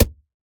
Minecraft Version Minecraft Version latest Latest Release | Latest Snapshot latest / assets / minecraft / sounds / block / packed_mud / break2.ogg Compare With Compare With Latest Release | Latest Snapshot